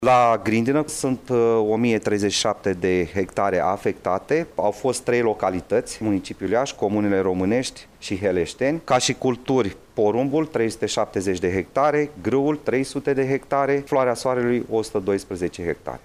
De asemenea, au fost afectate în proporţie de peste 50 la sută culturile de grâu, porumb şi floarea soarelui din localităţile Iaşi, Româneşti şi Heleşteni. Subprefectul de Iaşi, Bogdan Abălaşei: